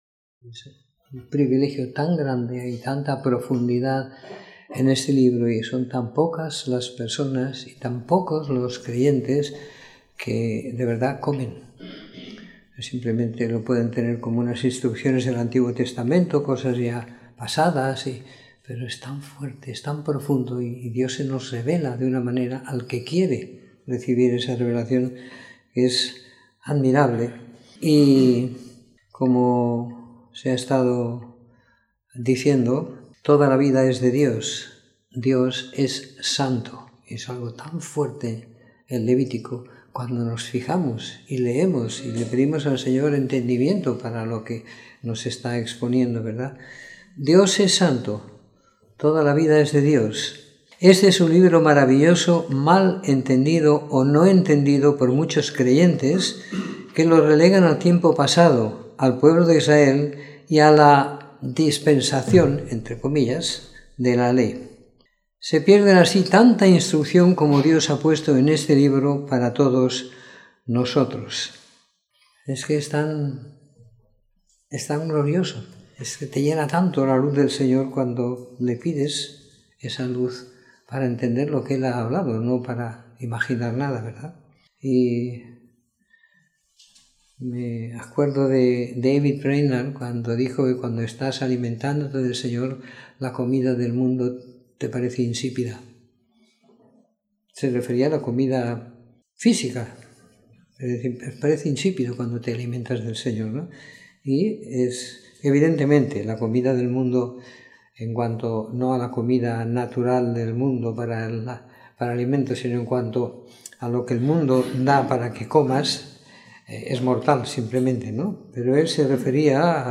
Comentario en el libro de Levítico del capítulo 1 al 15 siguiendo la lectura programada para cada semana del año que tenemos en la congregación en Sant Pere de Ribes.